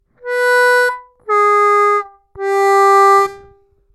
-3 -3'''+3 отрывисто